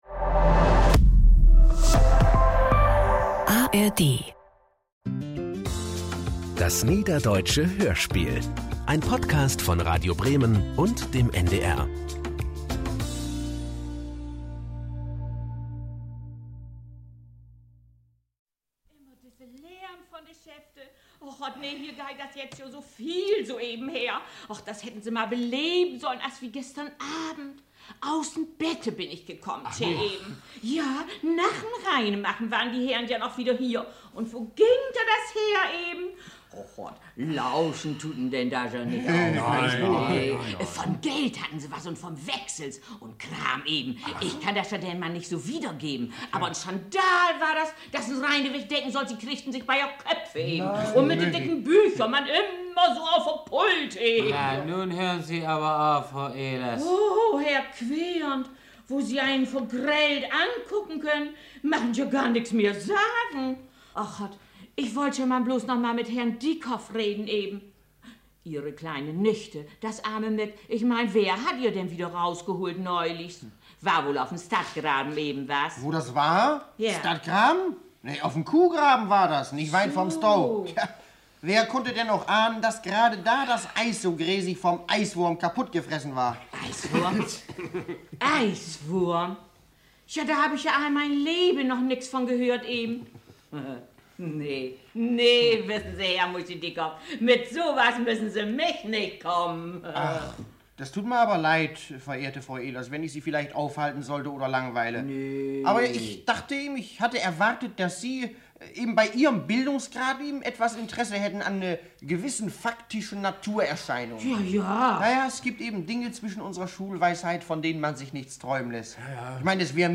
Aus den Geschichten machte Heinrich Schmidt-Barrien in den 1950er Jahren eine 16-teilige Hörspielserie. Die 7. Folge umfasst die Kapitel 12 und 13."Ottjen Alldag